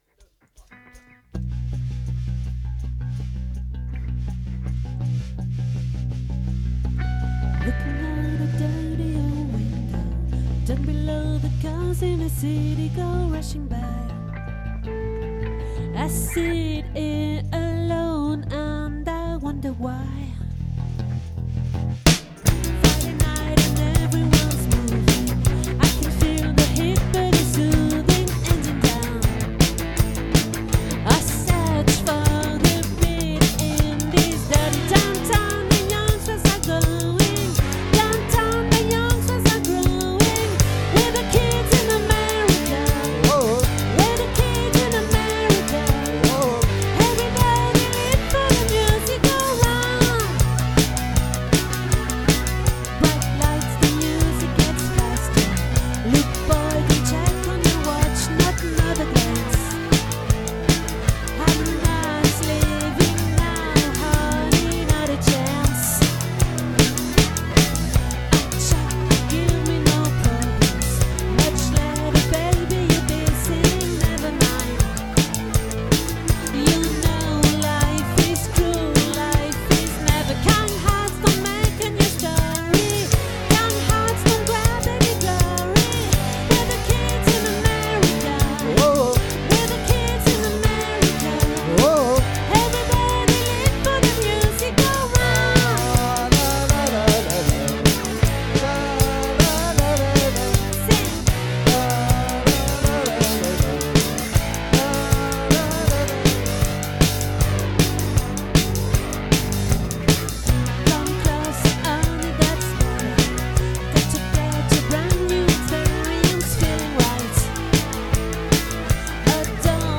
🏠 Accueil Repetitions Records_2025_02_17